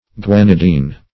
Search Result for " guanidine" : The Collaborative International Dictionary of English v.0.48: Guanidine \Gua"ni*dine\, n. (Physiol.
guanidine.mp3